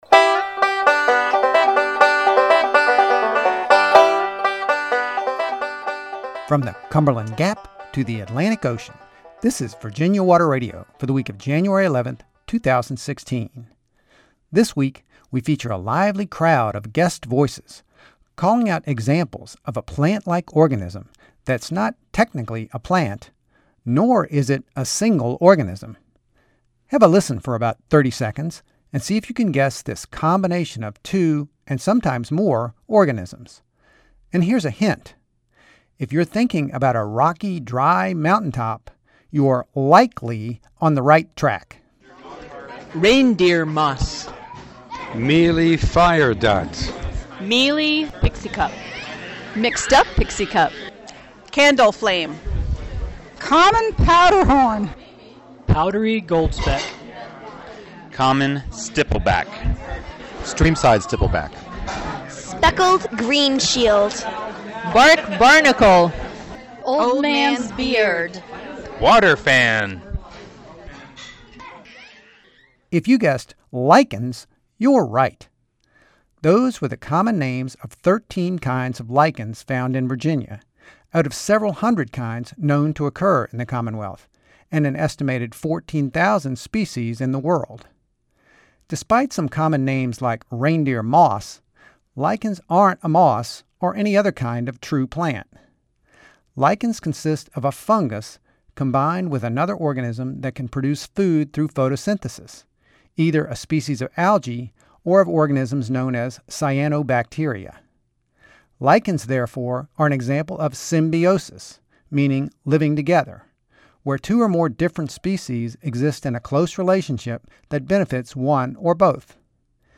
Thanks to friends in Blacksburg for recording the common names on several kinds of lichens, on Januar 6, 2016.